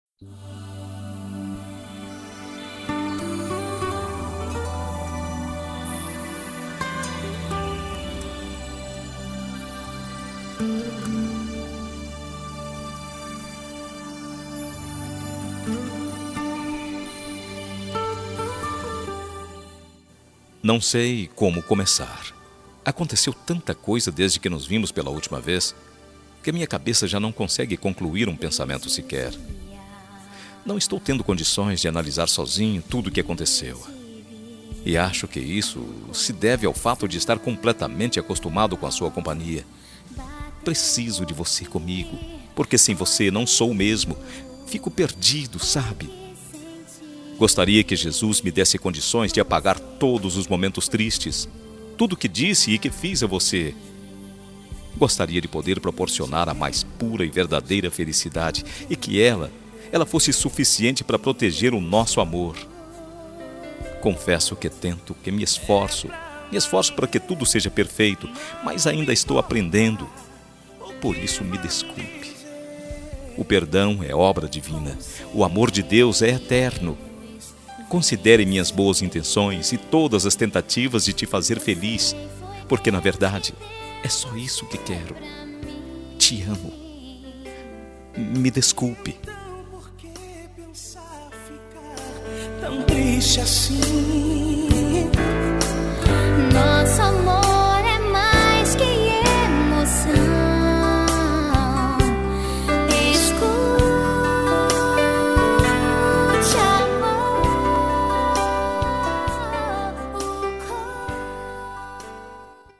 Telemensagem de Desculpas Evangélicas – Voz Masculina Cód: 74